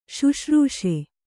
♪ śuśrūṣe